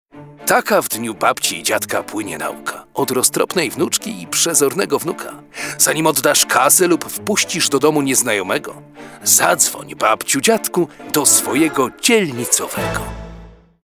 Specjalnie na tę wyjątkową okazję chojniccy policjanci prewencji, w ramach działań profilaktycznych, wspólnie z Radiem Weekend FM przygotowali radiowy spot skierowany do seniorów. Przestrzegają w nich jak działają oszuści podszywający się pod bliskich lub samych policjantów. Policyjne dykteryjki odczytał jeden z najbardziej znanych, polskich lektorów Pan Rino Pawletta.